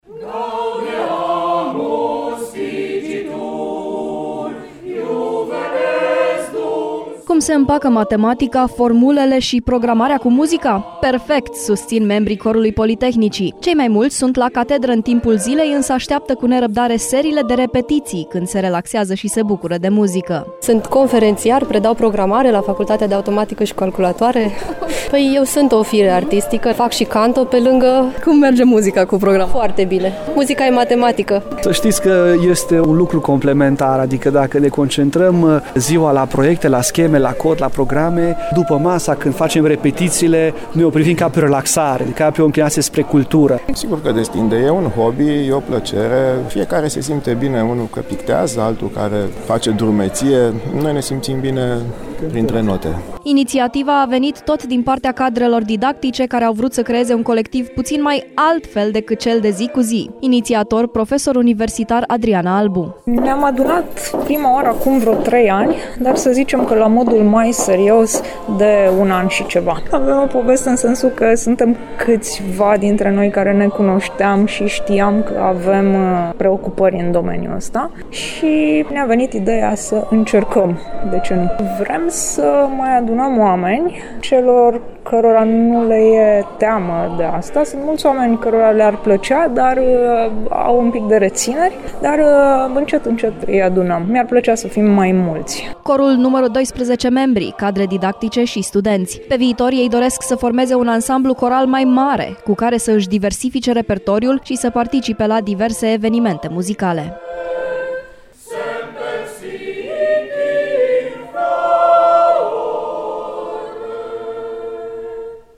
Cu aceeași ocazie a susținut un recital și Corul Politehnicii, format din ingineri, cadre didactice și studenți.